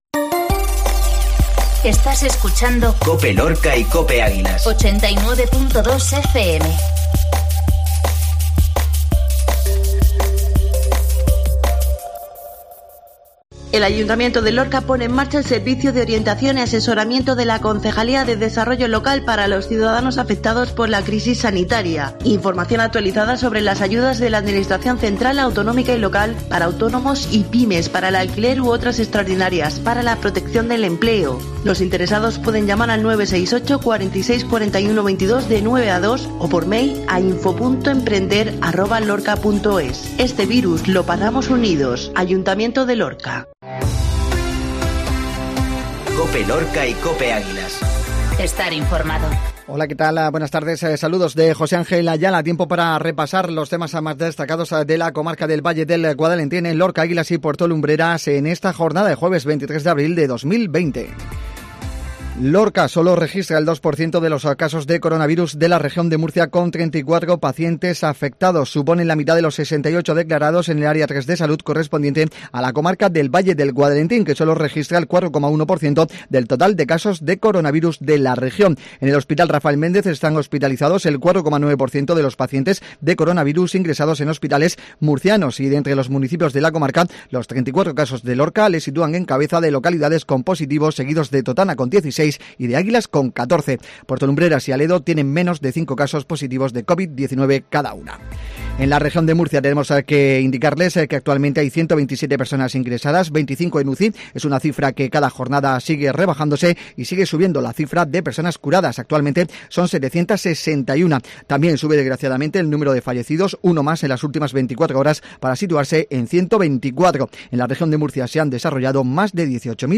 INFORMATIVO MEDIODIA COPE LORCA